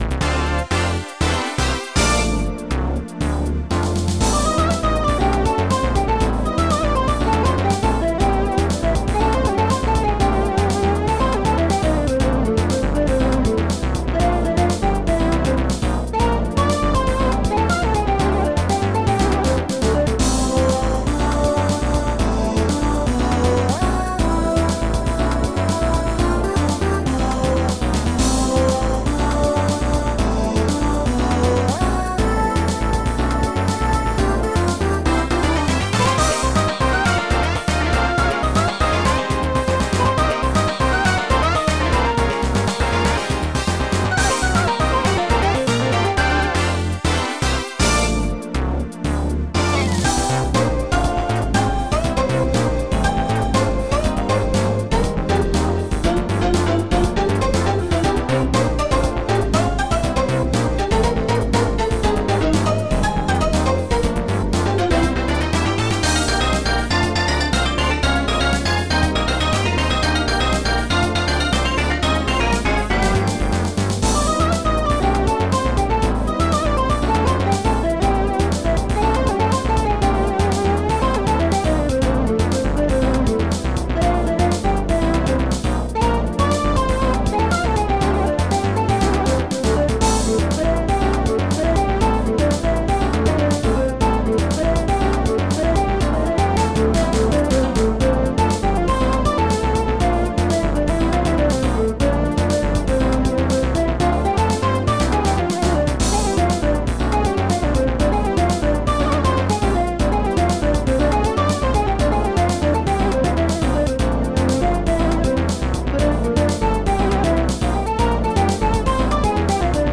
ST-42:emu.hihat4
ST-54:dd.cymbal2
ST-38:mezzo.moog
ST-42:xp.ob-string